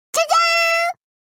分类：情绪